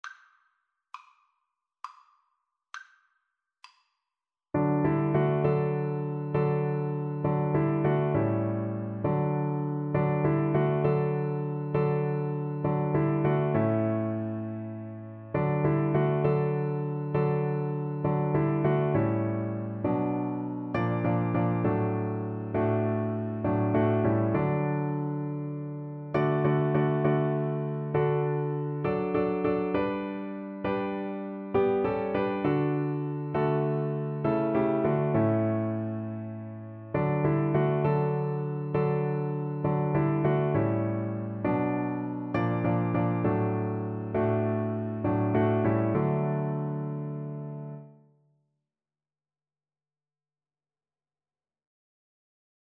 Play (or use space bar on your keyboard) Pause Music Playalong - Piano Accompaniment Playalong Band Accompaniment not yet available reset tempo print settings full screen
9/8 (View more 9/8 Music)
D major (Sounding Pitch) (View more D major Music for Viola )